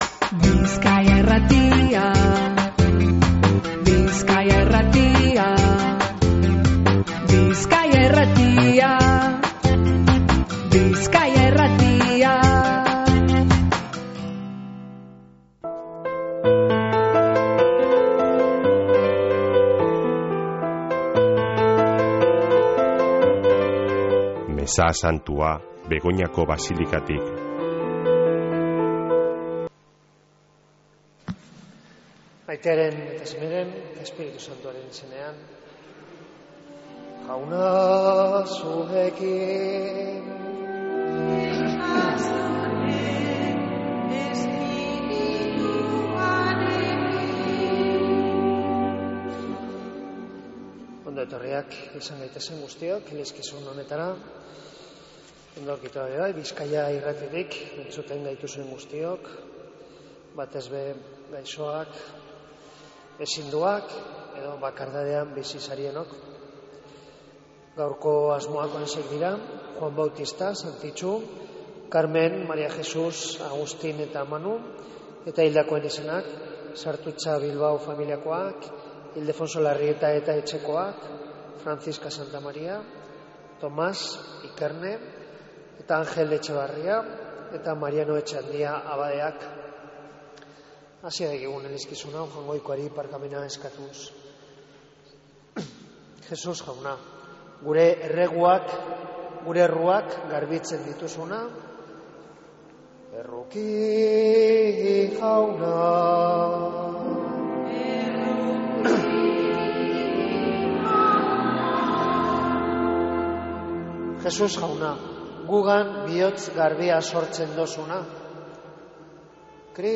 Mezea Begoñako Basilikatik | Bizkaia Irratia